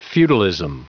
Prononciation du mot feudalism en anglais (fichier audio)
Prononciation du mot : feudalism